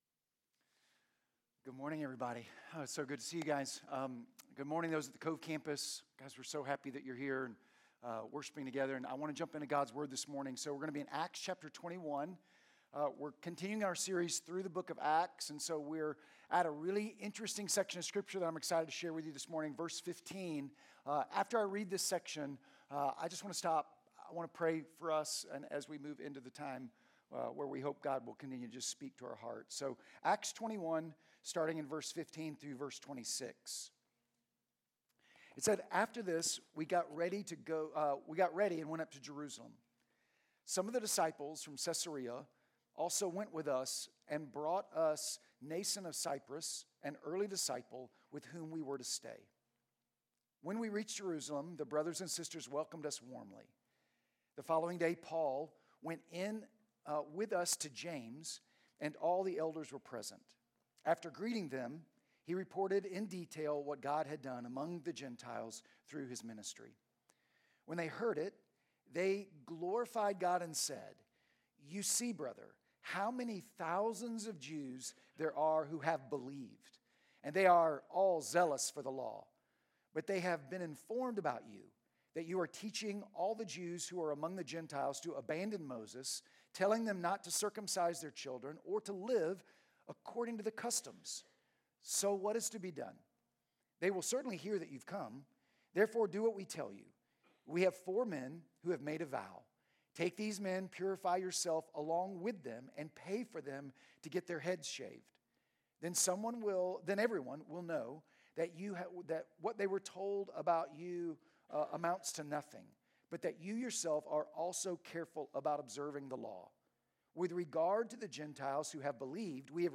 Sermon Notes Sermon Audio…